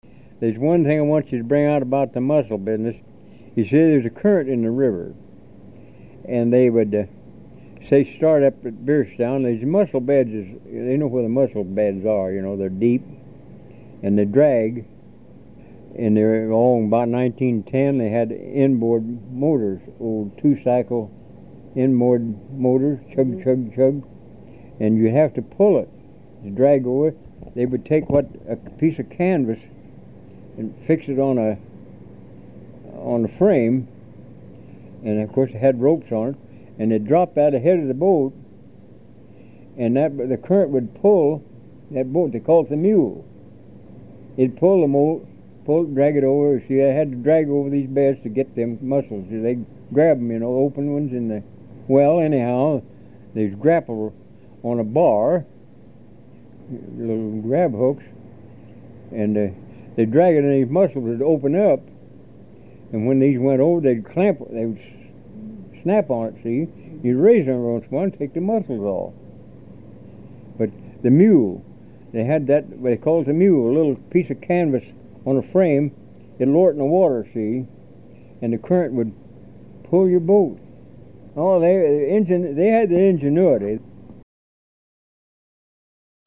HTR Oral History, 07/17/1